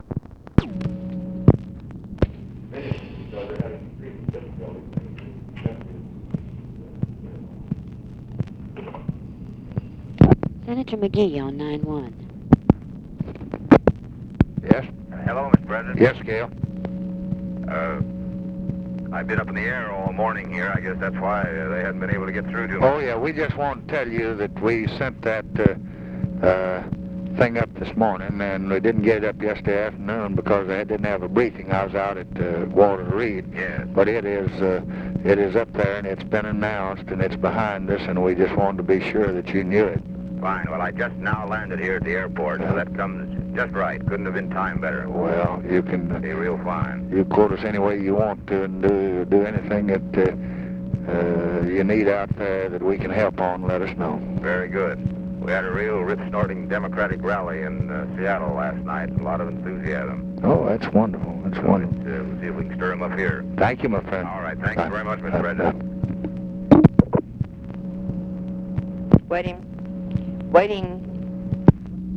Conversation with GALE MCGEE, May 13, 1966
Secret White House Tapes